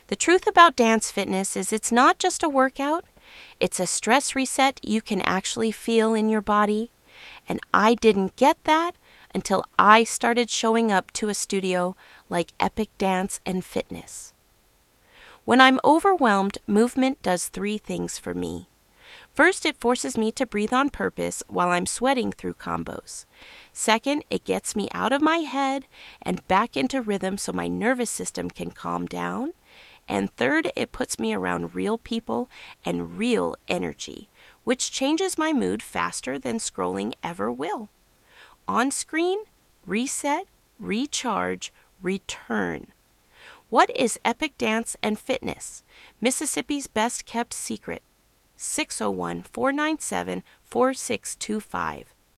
VOICEOVER video with natural voice (no AI): quick motivation + 3 ways dance fitness helps reset stress.